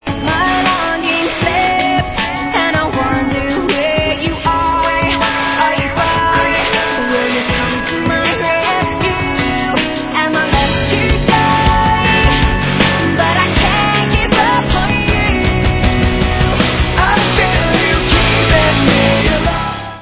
sledovat novinky v oddělení Alternative Rock
Rock